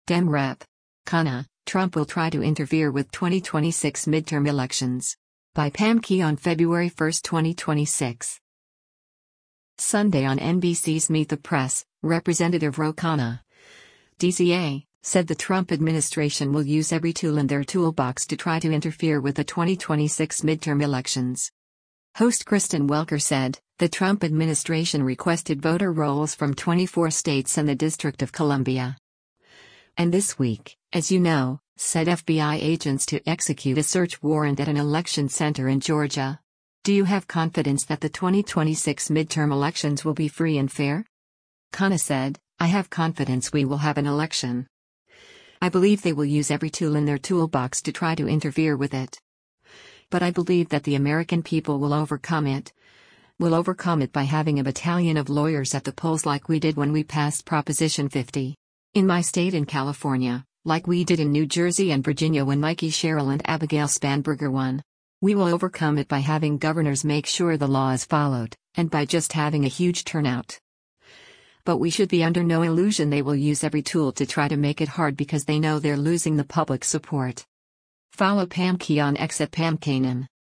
Sunday on NBC’s “Meet the Press,” Rep. Ro Khanna (D-CA) said the Trump administration will “use every tool in their toolbox to try to interfere” with the 2026 midterm elections.